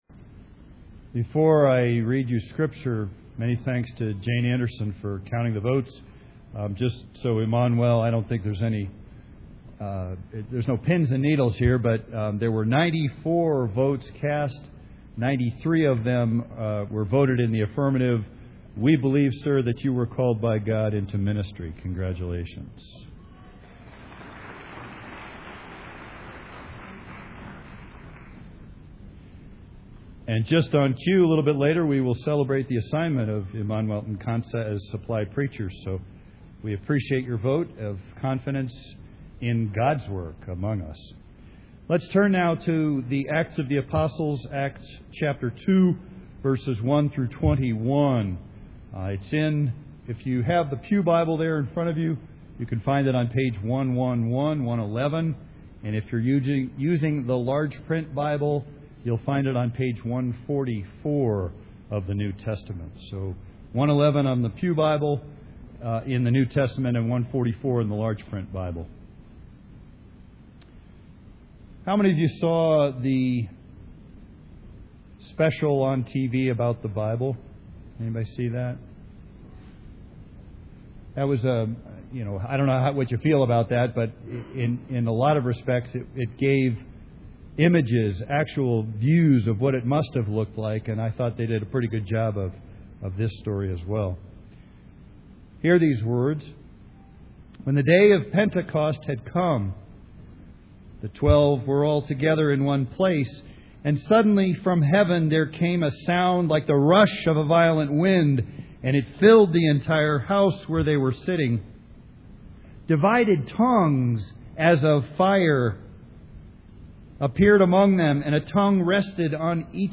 Pentecost Sunday Worship Service
Scripture Reading